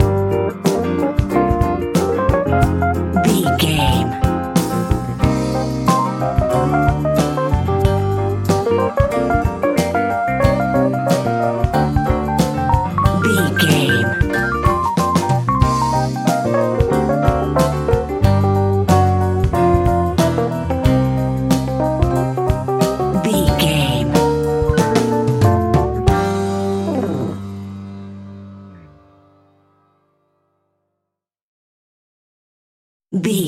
Epic / Action
Fast paced
In-crescendo
Uplifting
Ionian/Major
hip hop
instrumentals